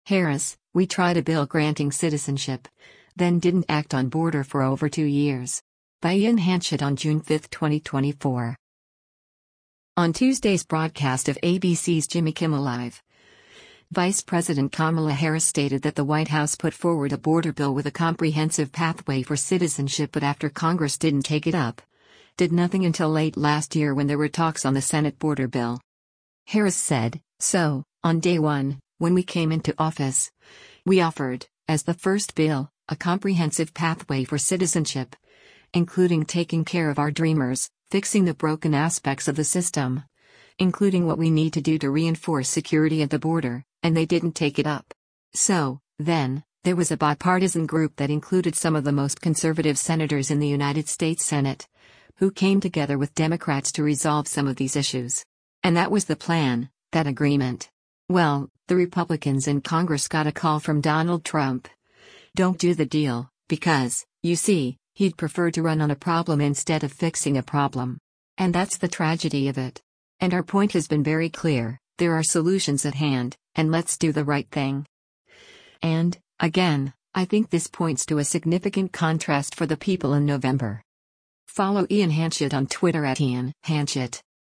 On Tuesday’s broadcast of ABC’s “Jimmy Kimmel Live,” Vice President Kamala Harris stated that the White House put forward a border bill with “a comprehensive pathway for citizenship” but after Congress didn’t take it up, did nothing until late last year when there were talks on the Senate border bill.